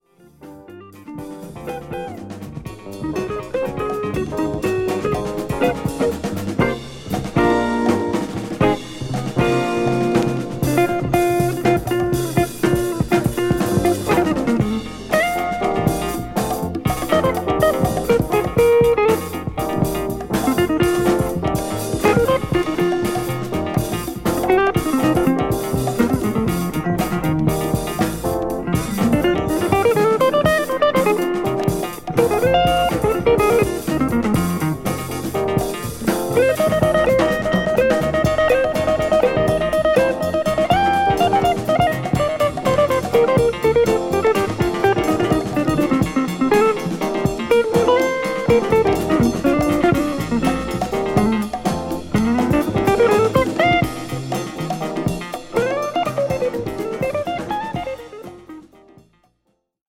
JAZZ FUNK
ライブ感たっぷりのナイス・ジャズ・ファンク